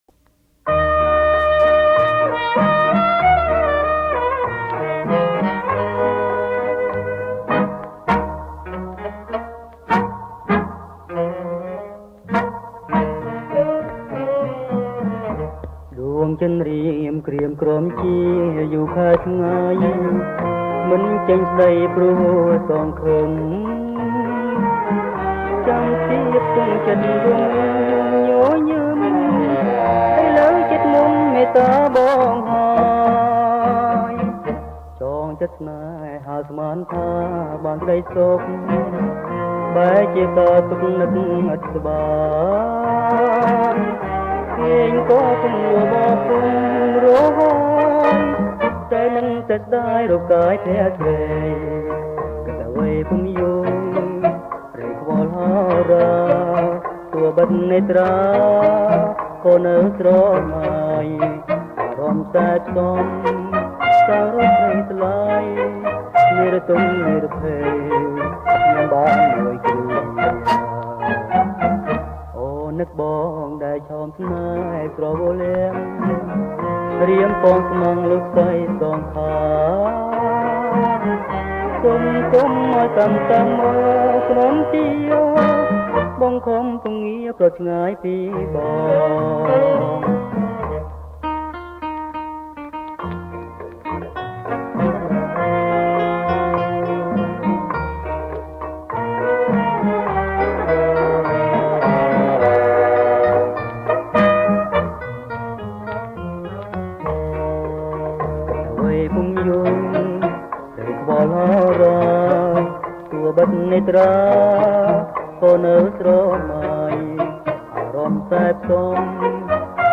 ប្រគំជាចង្វាក់  Boléro